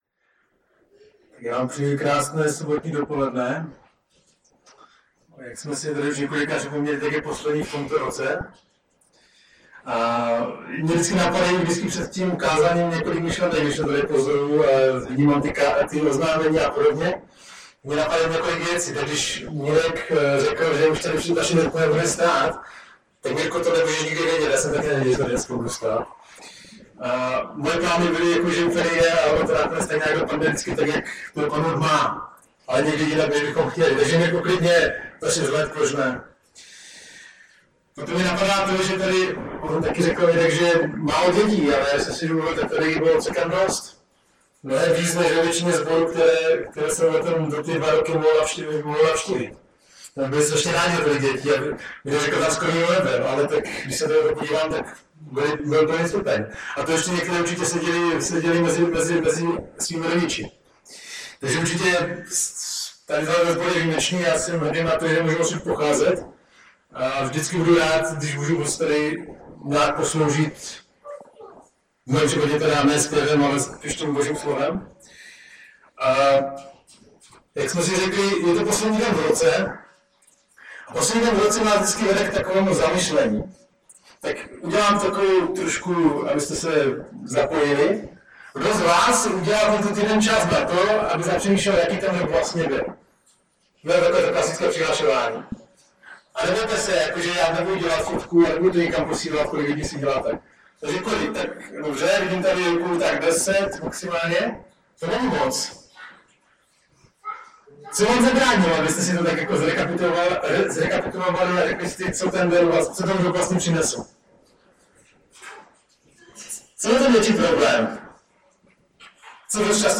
Omluvte sníženou kvalitu zvuku.
Kazatel